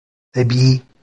Pronunciado como (IPA)
/tɑˈbiː/